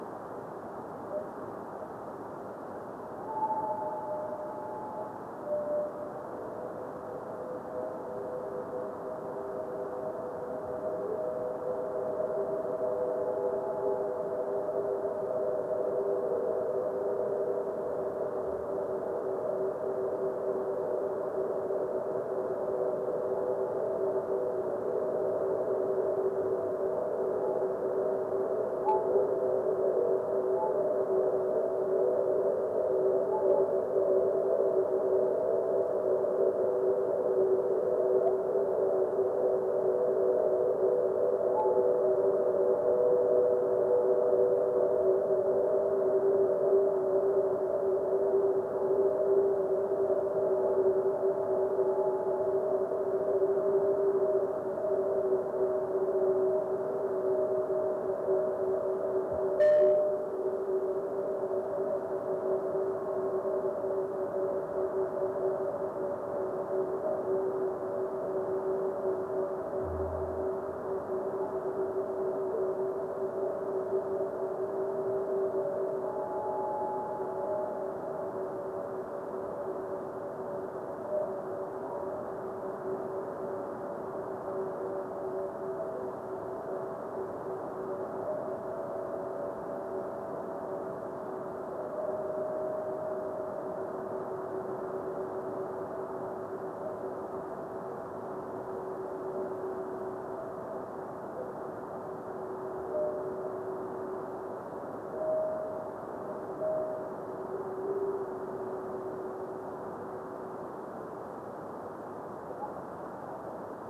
Sonic Movie 2 : Full duration of radio reflection.
The radio reflection off the fireball's ionization was strong and long lasting.
And here is just the extracted forward scatter sound as an .mp3.  1.7 MB   1 minute 55 seconds